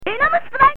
Japanese
Some of the voices were also changed, here is an example with one of King's special moves.